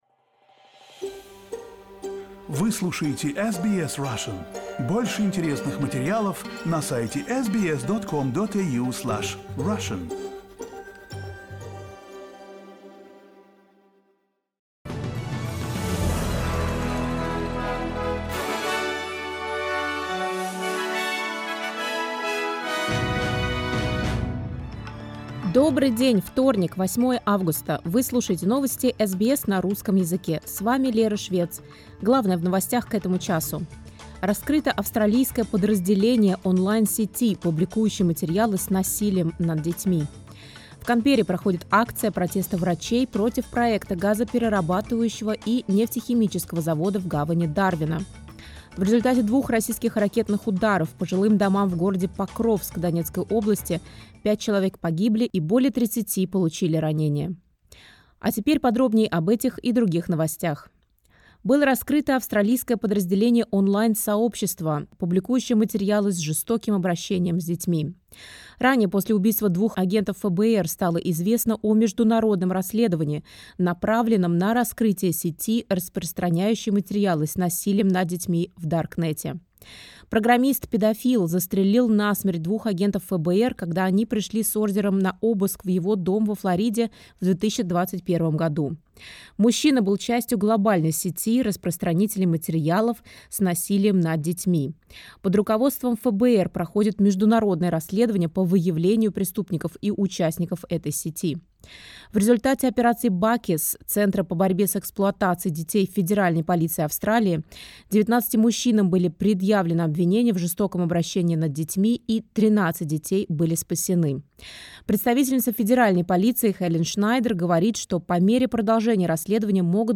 SBS news in Russian — 08.08.2023